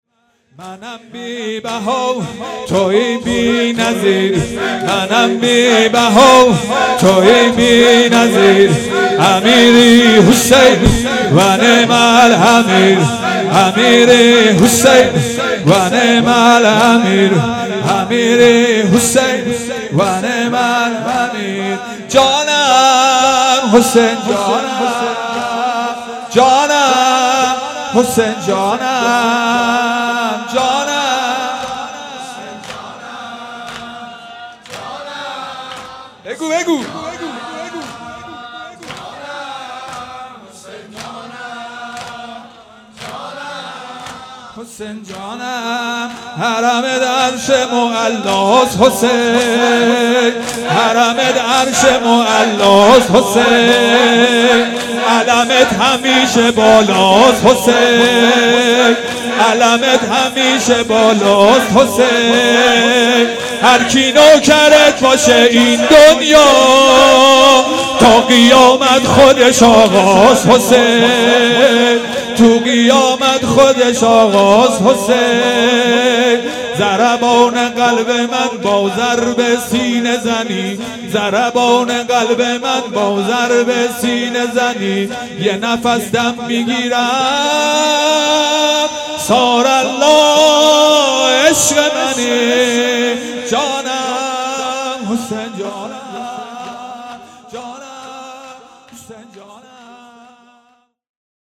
شور
شب چهارم محرم الحرام 1441